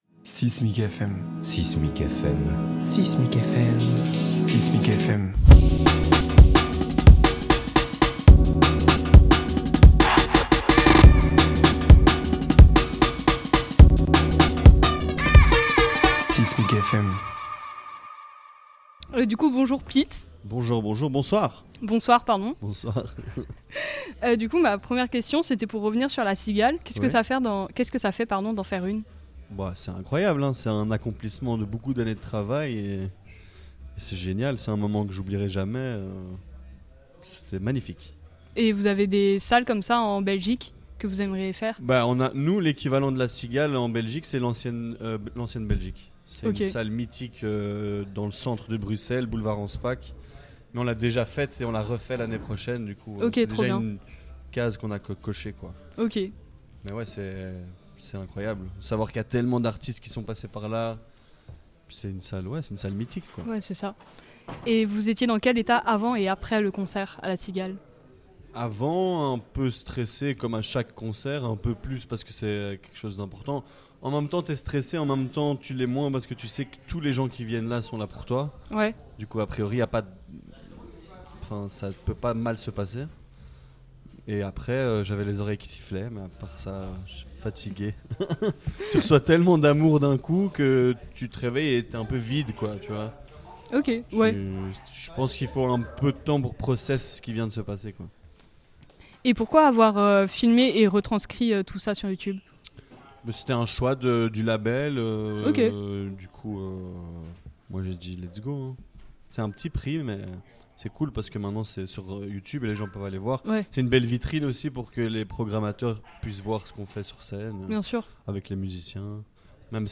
6mic FM est une nouvelle collaboration entre 6mic et RadioZai où les musiciens discutent avec nous dans ce lieu emblématique d'Aix-en-Provence.